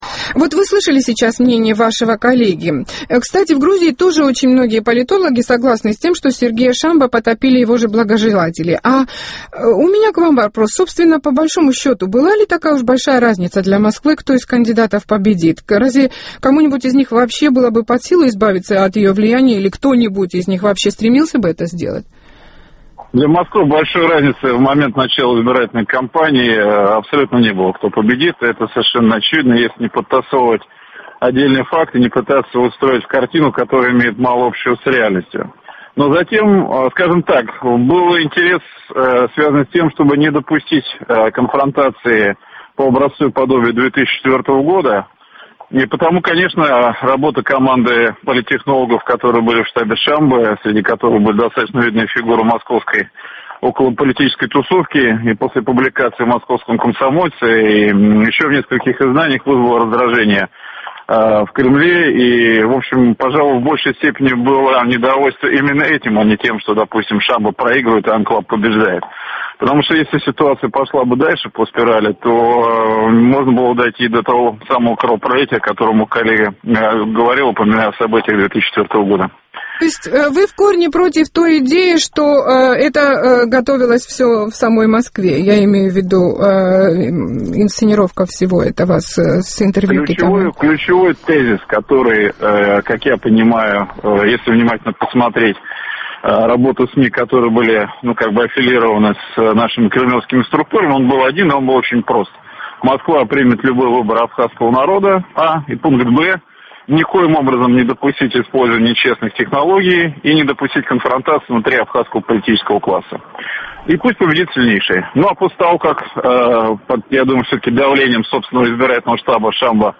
Беседа с экспертом